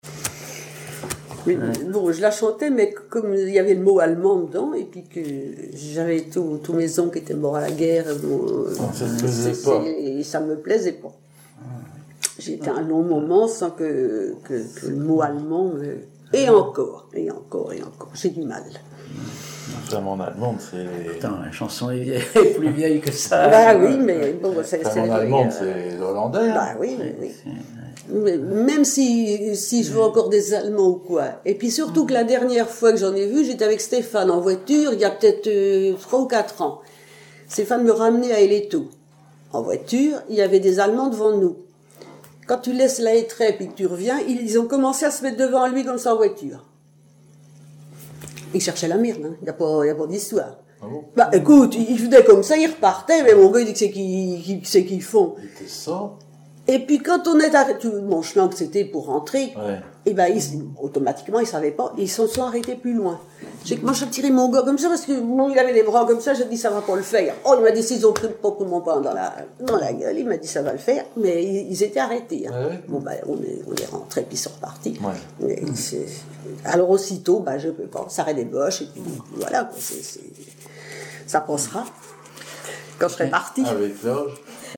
Mémoires et Patrimoines vivants - RaddO est une base de données d'archives iconographiques et sonores.
Commentaire sur la chanson La Flamande allemande
Catégorie Témoignage